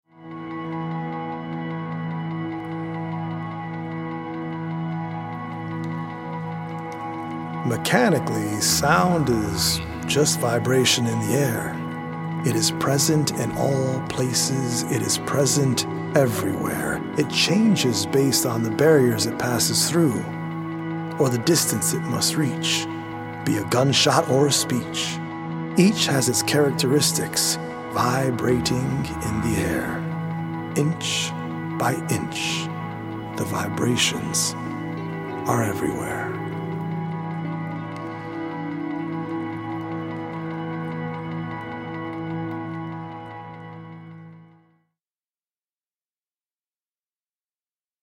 Senses is a healing audio-visual poetic journey through the mind-body and spirit that is based on 100 original poems written/performed by
healing Solfeggio frequency music